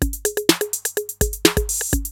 TR-808 LOOP2 2.wav